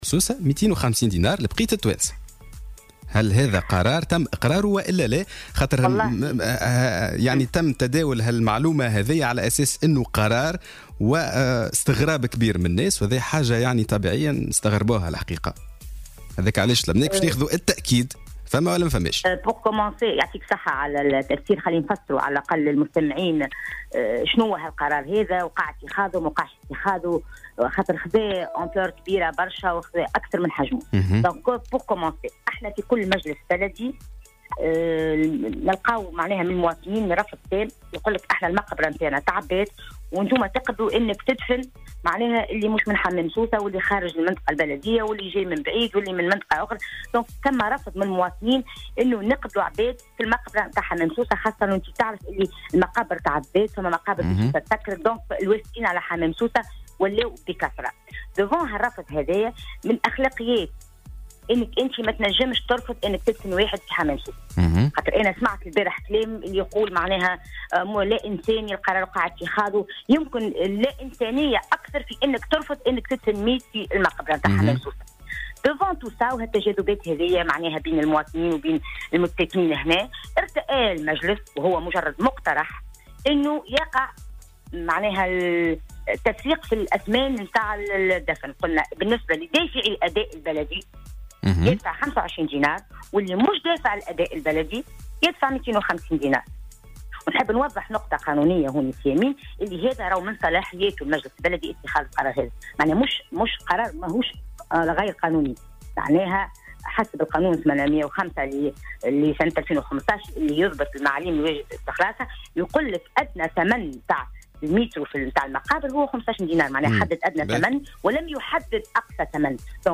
أكدت معتمدة حمام سوسة ورئيسة النيابة الخصوصية منية الجويني في مداخلة لها في صباح الورد اليوم الجمعة 2 مارس 2018 أن المجلس البلدي تلقى تشكيات من قبل المواطنين في حمام سوسة ورفض لدفن "أغراب" وافدين على الجهة من مناطق مجاورة في مقبرتهم بسبب عدم وجود أماكن فيها إلا لمتساكني الجهة.